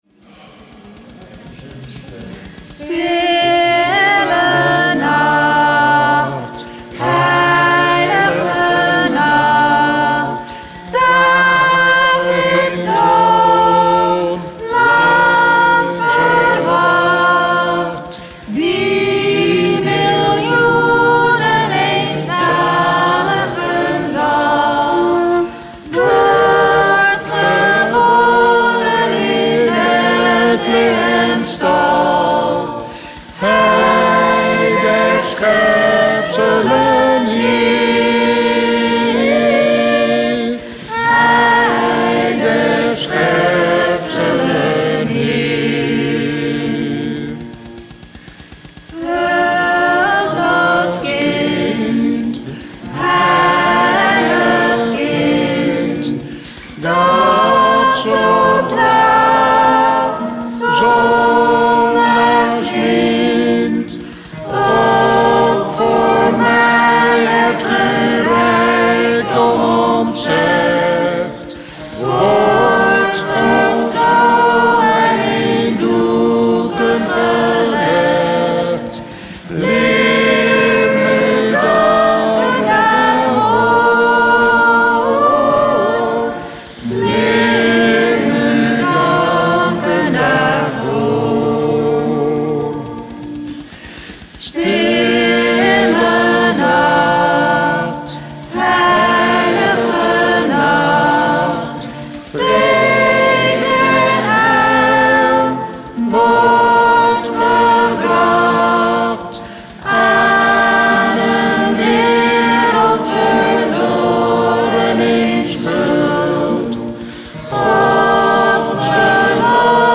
Er is veel muziek met veel muzikanten er wordt gelezen uit Lucas 2: 1-16